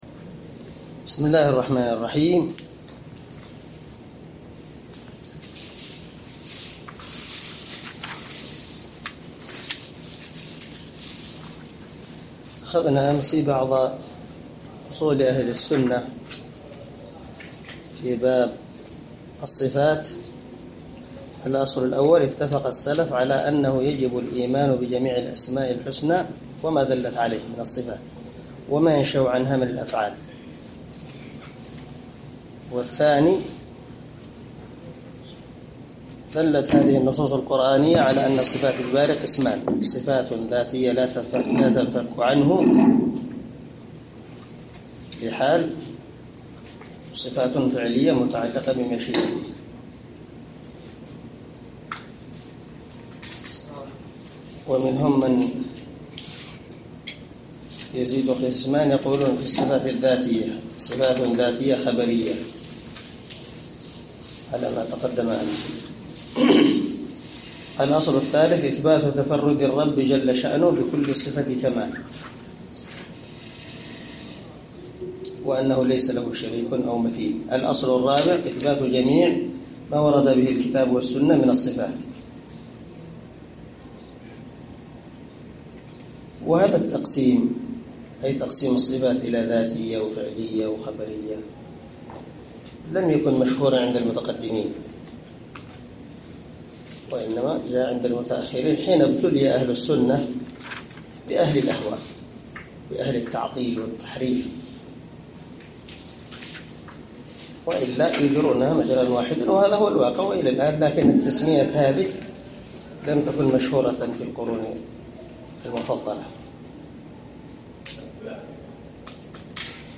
شرح العقيدة الواسطية للعلامة محمد بن خليل هراس رحمه الله – الدرس الخامس والأربعون
دار الحديث- المَحاوِلة- الصبيحة.